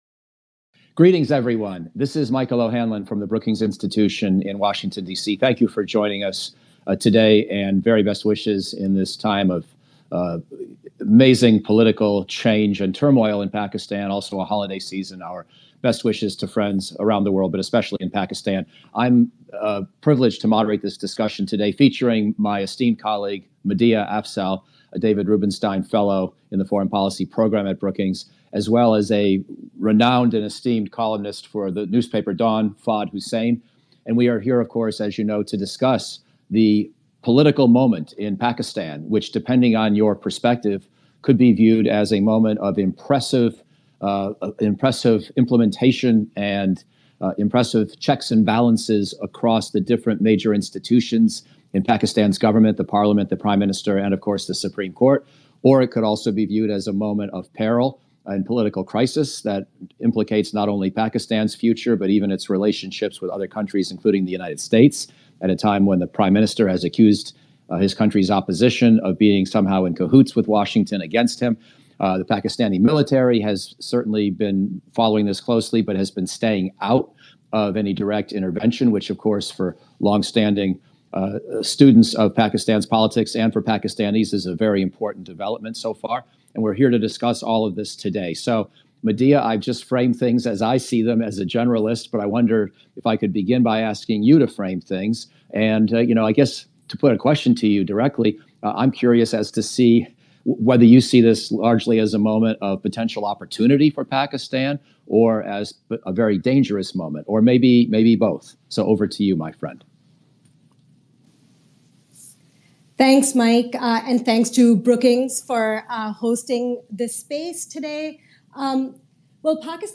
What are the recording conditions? Pakistan-Twitter-Space-April-2022.mp3